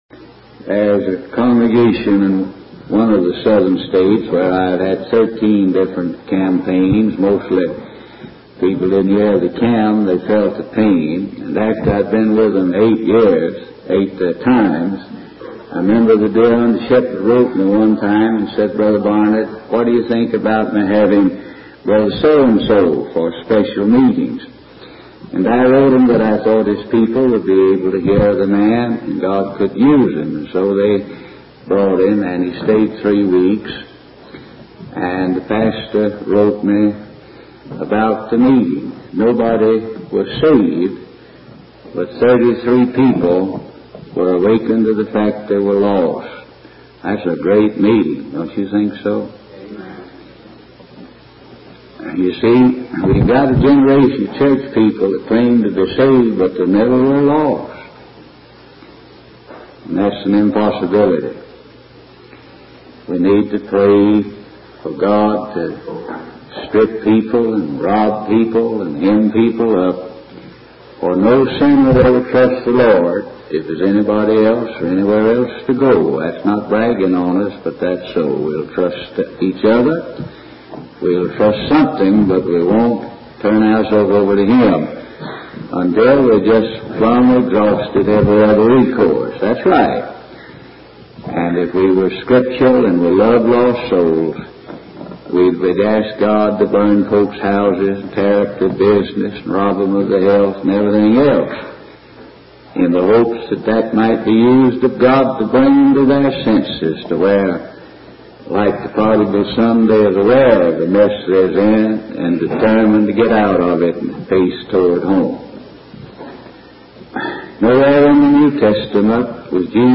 In this sermon, the preacher discusses the concept of death and what happens to a person's body and spirit after they die. He emphasizes that according to the Bible, when a person dies, their body returns to the earth and their spirit returns to God. The preacher shares a personal story of a conversation with a guard in a death row prison, who witnessed a condemned woman's fear of death.